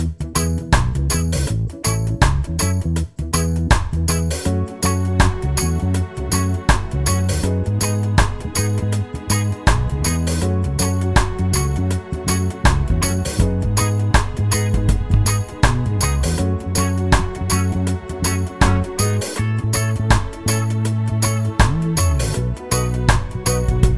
no Backing Vocals Reggae 2:55 Buy £1.50